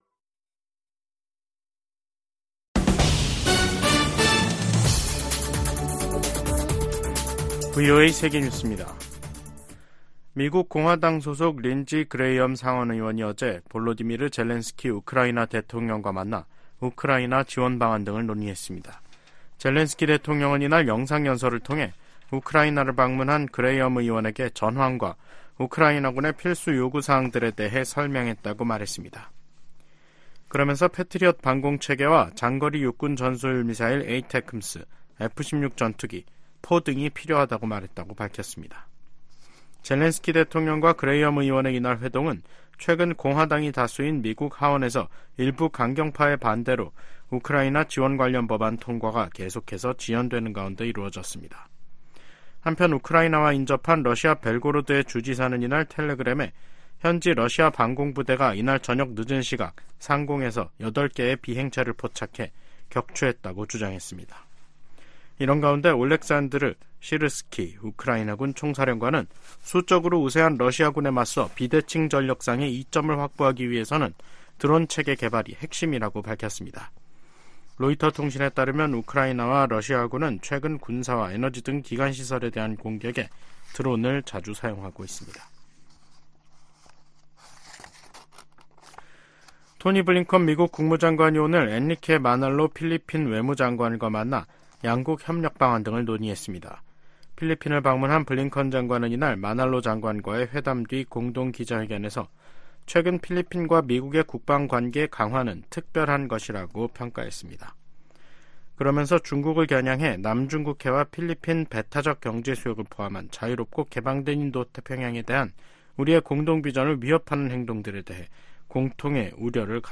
VOA 한국어 간판 뉴스 프로그램 '뉴스 투데이', 2024년 3월 19일 2부 방송입니다. 린다 토머스-그린필드 유엔 주재 미국 대사가 북한의 17일 단거리 탄도미사일 발사를 비판했습니다. 김정은 북한 국무위원장은 18일 한국 수도권 등을 겨냥한 초대형 방사포 사격훈련을 지도하며 위협 수위를 높였습니다. 미국 대선과 한국 총선을 앞둔 올해 북한의 임박한 공격 징후는 보이지 않는다고 정 박 미 국무부 대북 고위관리가 말했습니다.